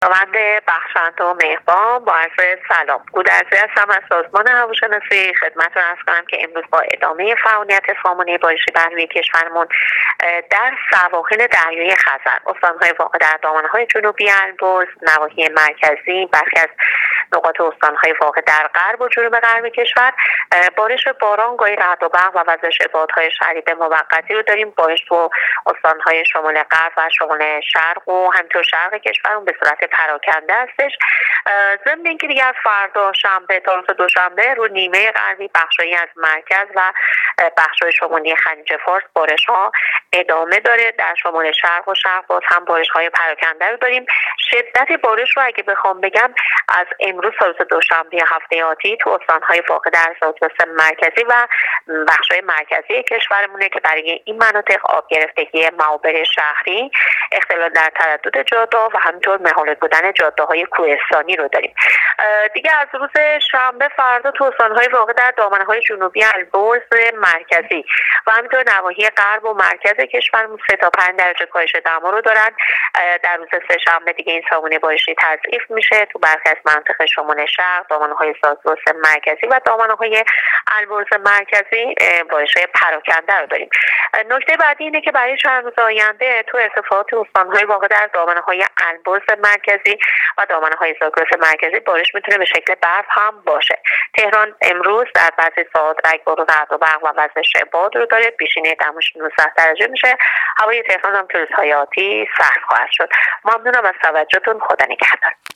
گزارش رادیو اینترنتی وزارت راه و شهرسازی از آخرین وضعیت آب و هوای سوم آبان/ بارش باران در بیشتر نقاط کشور/ شدت بارش‌ها بر زاگرس مرکزی متمرکز است